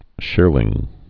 (shîrlĭng)